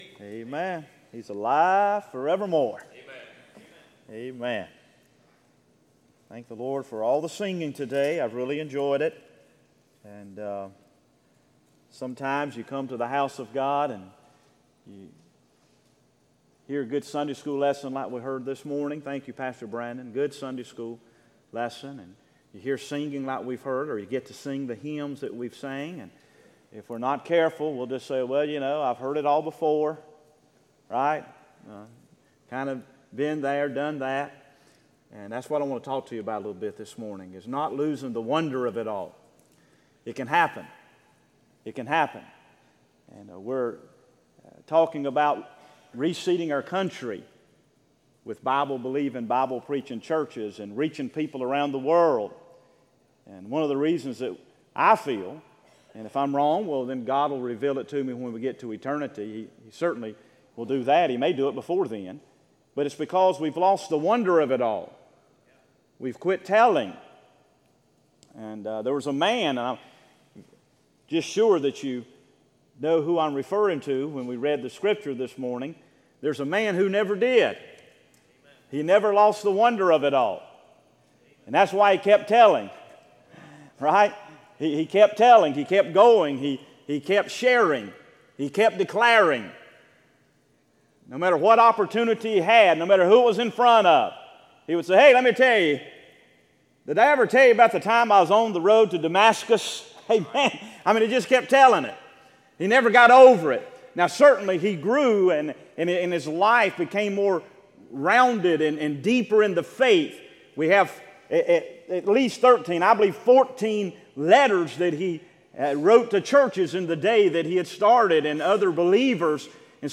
Sermons Archive • Page 61 of 166 • Fellowship Baptist Church - Madison, Virginia